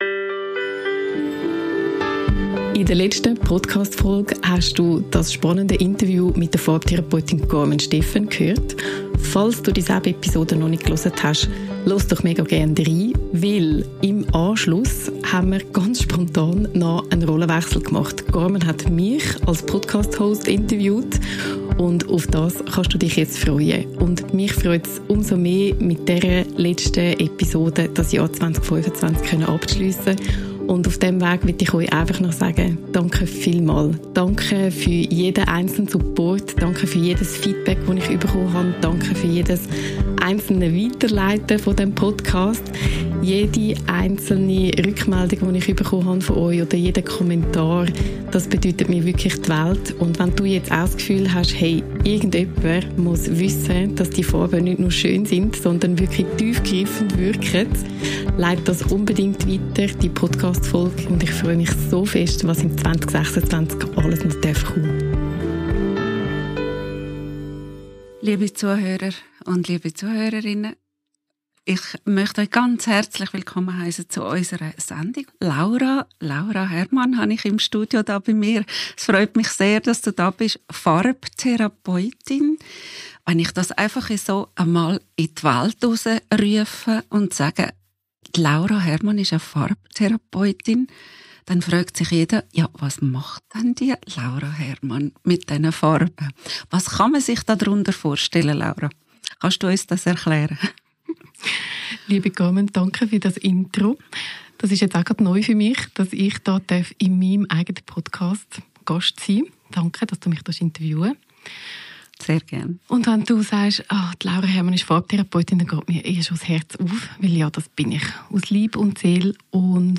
#21 Interview